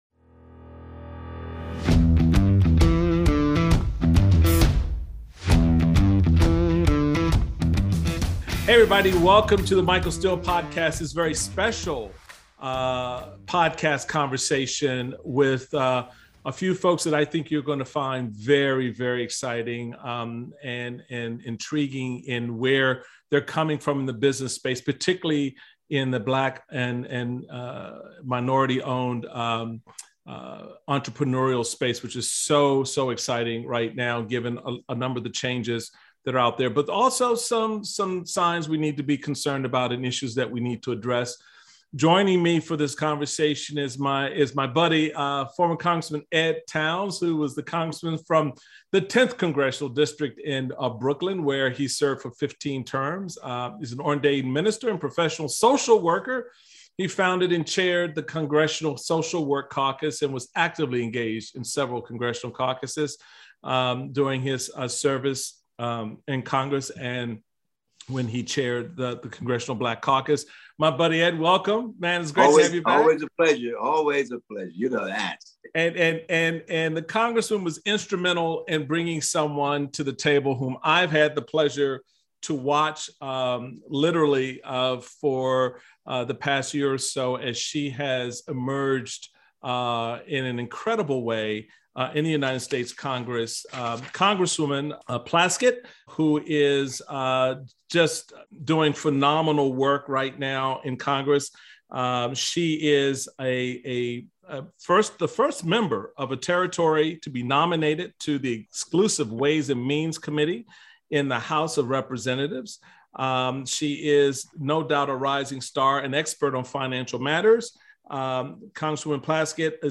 Roundtable Discussion on Minority Business Ownership, Entrepreneurship, and Investment